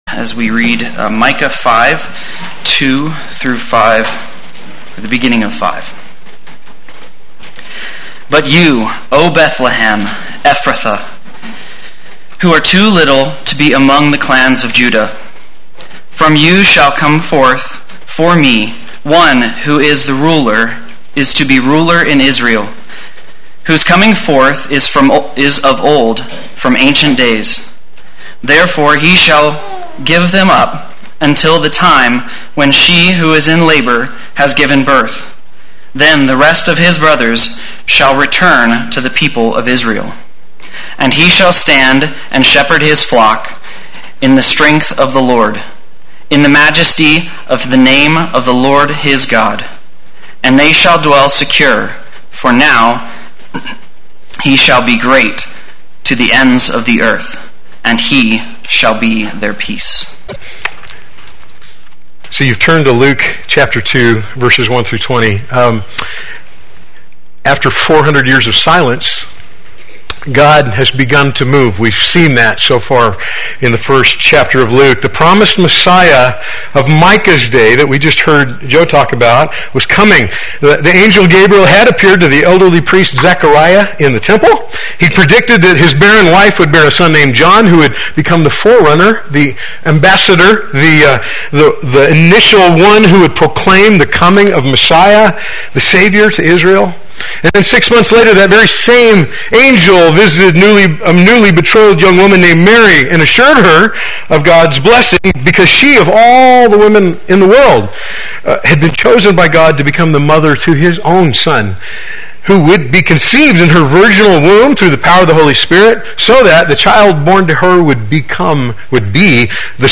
Play Sermon Get HCF Teaching Automatically.
Responding to the Birth of the Savior Sunday Worship